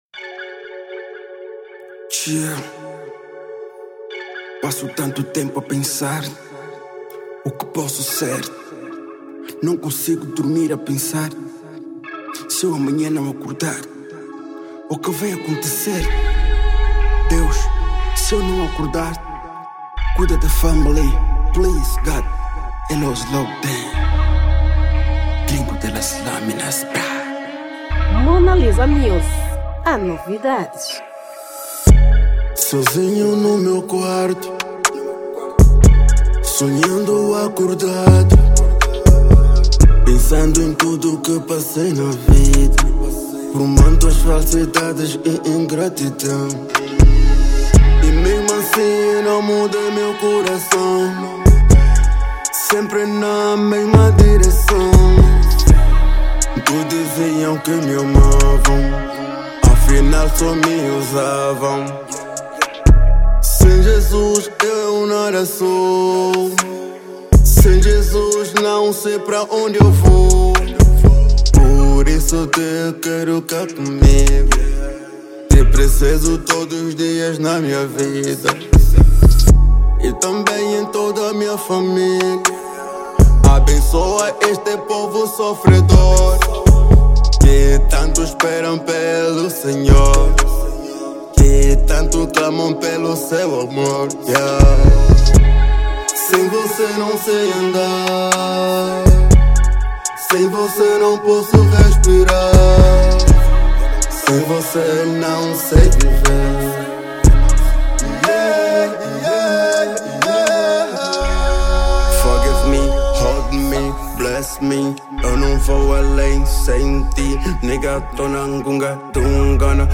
Gênero : Trap